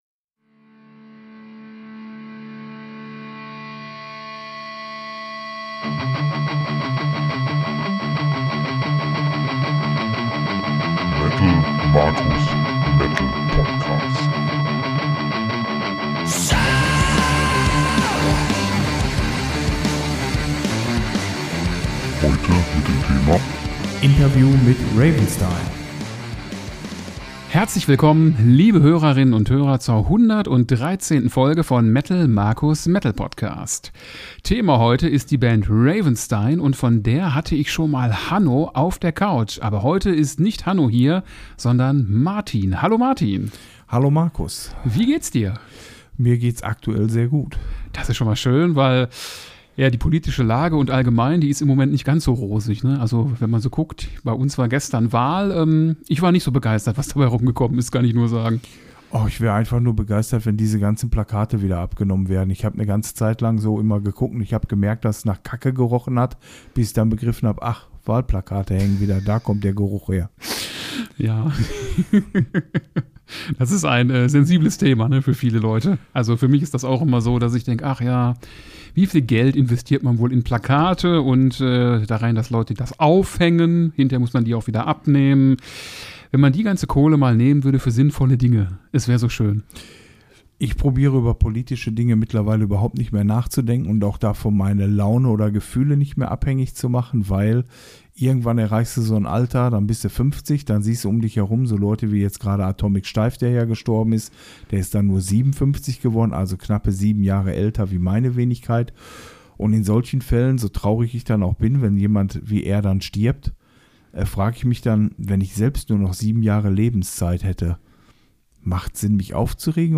#113 - Interview mit Ravenstine